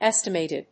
音節és・ti・màt・ed 発音記号・読み方
/‐ṭɪd(米国英語), ˈestʌˌmeɪtʌd(英国英語)/